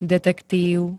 detektív [d-t-t-] -va pl. N -vi m.
Zvukové nahrávky niektorých slov